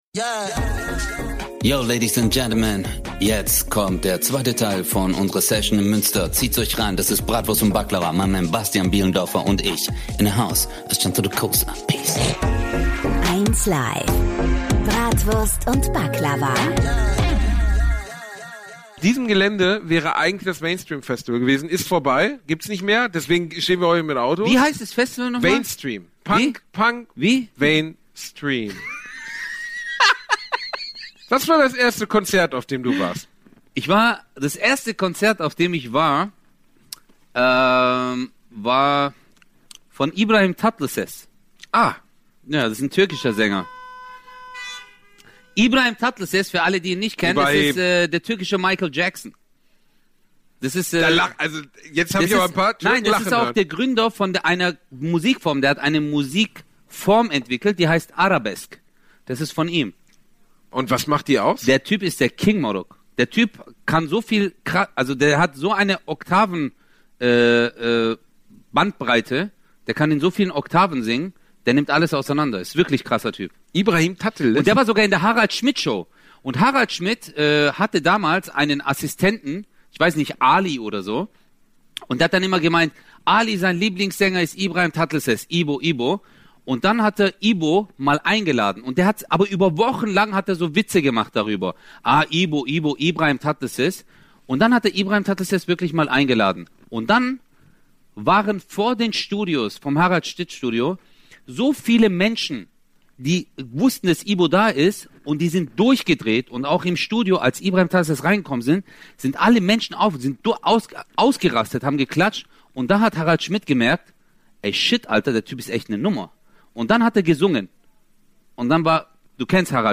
#47 Pharao Özcanamun - live im Autokino Münster ~ Bratwurst und Baklava - mit Özcan Cosar und Bastian Bielendorfer Podcast